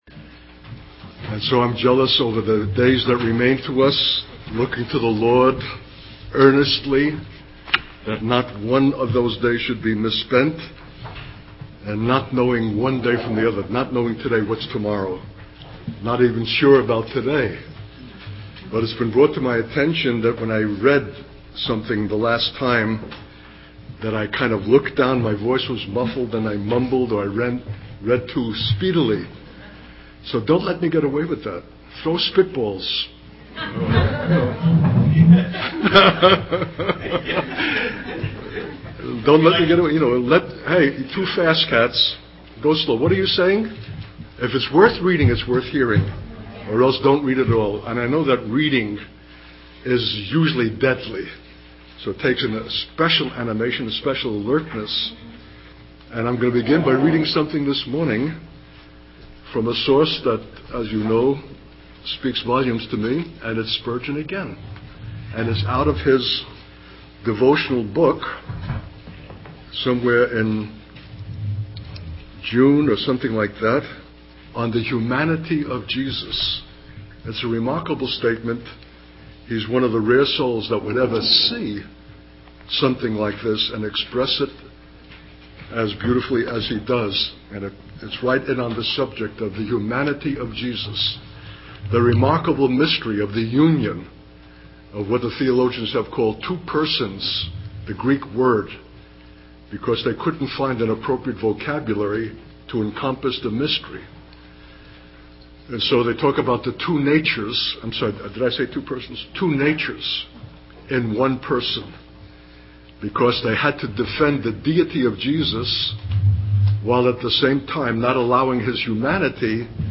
In this sermon, the speaker shares his experience of arriving late to a meeting and feeling out of place.